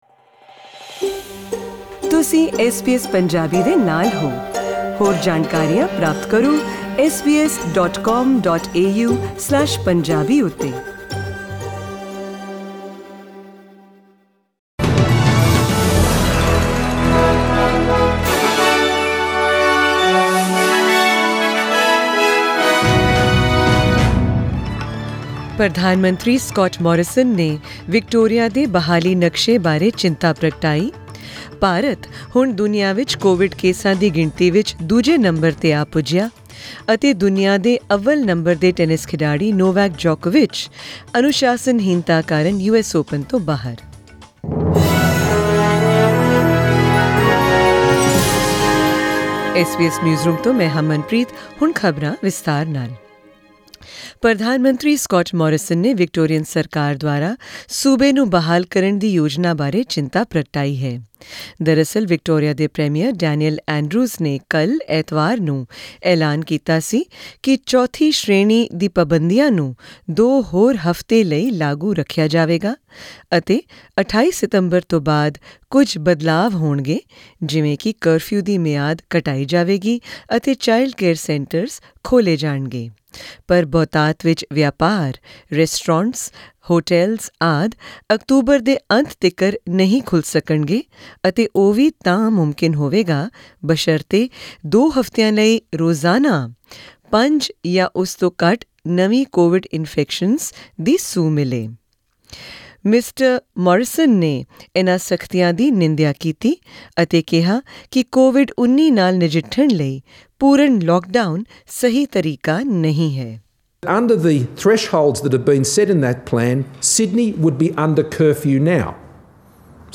In tonight's SBS Punjabi news bulletin: